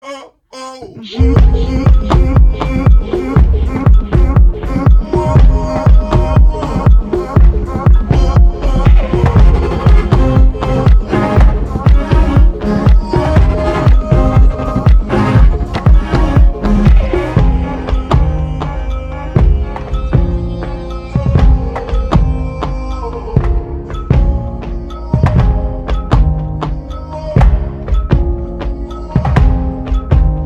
поп
инструментал , без слов , грустные